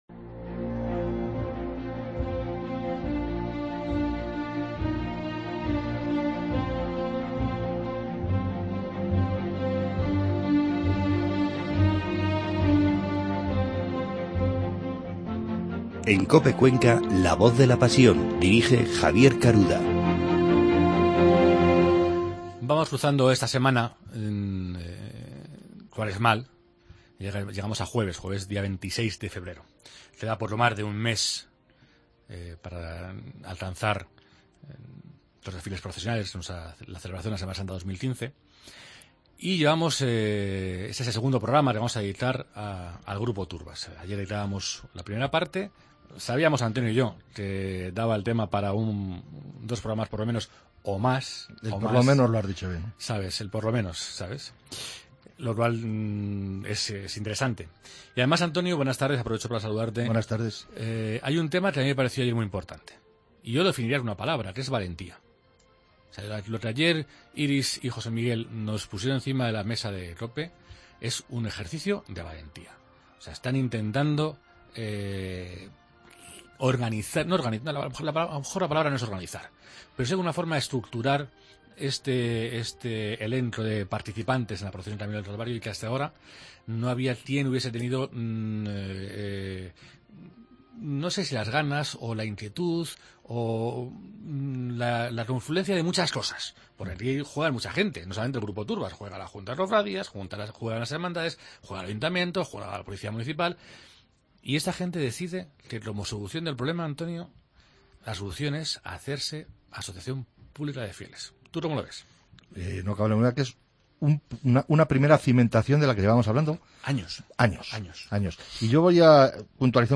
Continuamos nuestra charla con el Grupo Turbas.